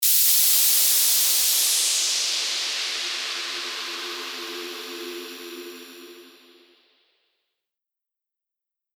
FX-1855-WHOOSH
FX-1855-WHOOSH.mp3